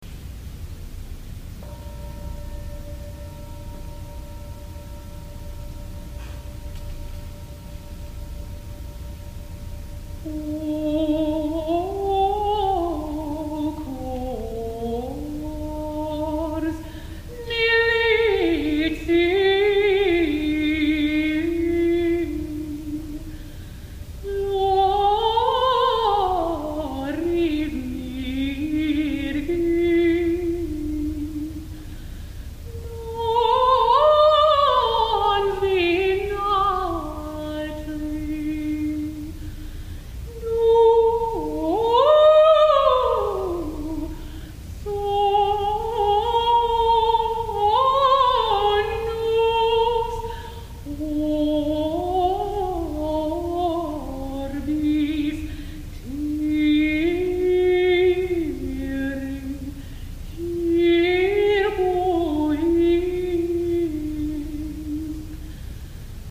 Texts and Translations of Hildegard von Bingen's Gregorian Chants
freeclips.o_cohors_liveumma.mp3